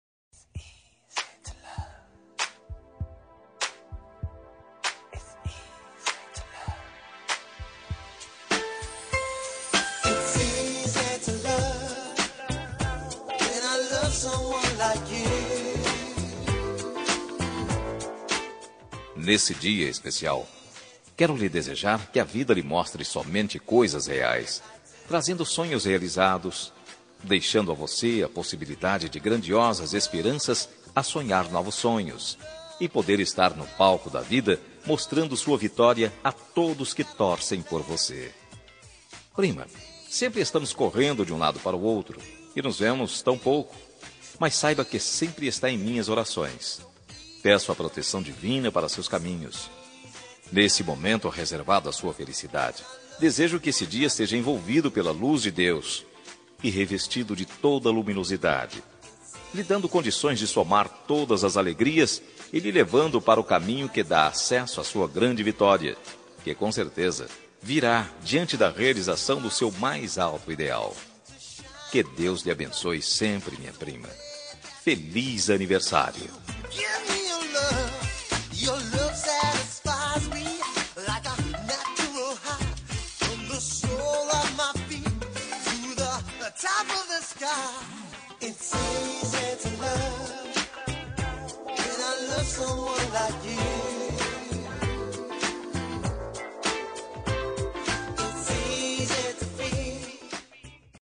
Aniversário de Prima – Voz Masculina – Cód: 042815